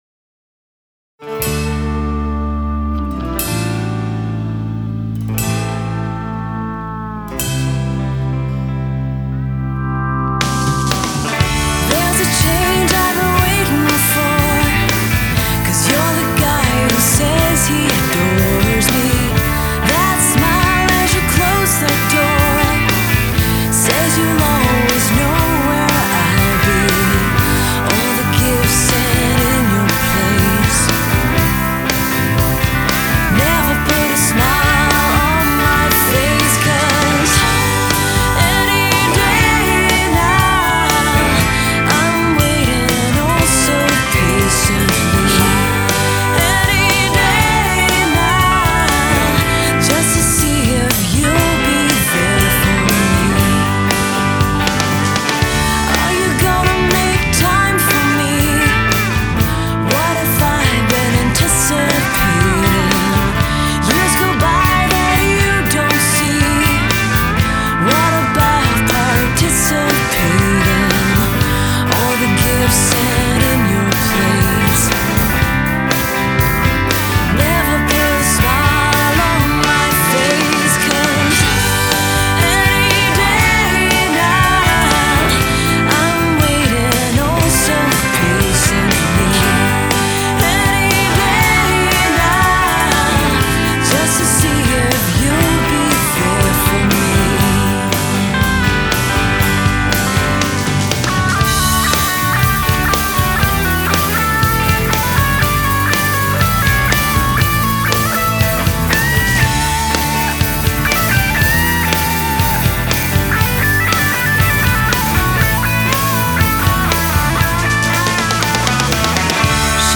Rock Pop Rockabilly Reggae Ska Country Rock
vocals